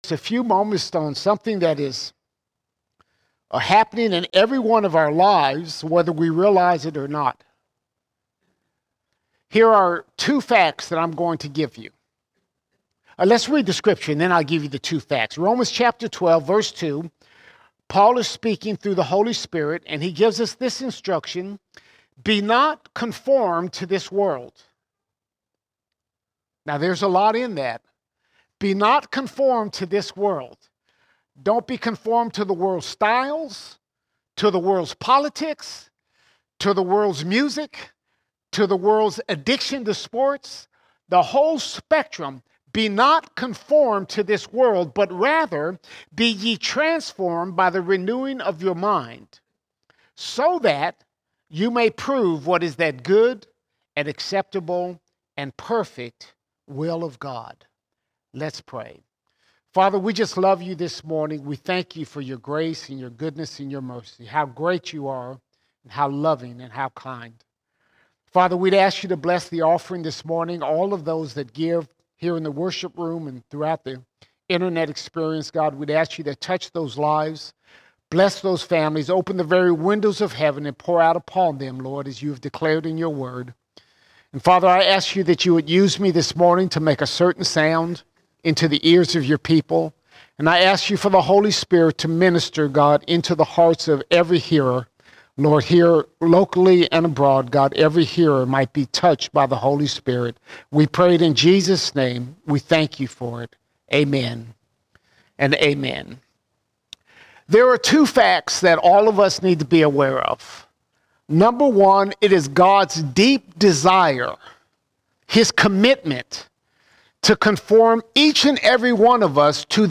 18 November 2024 Series: Sunday Sermons Topic: the world All Sermons Conforming Conforming We are to be conforming, not to this world, but to the image of Jesus Christ.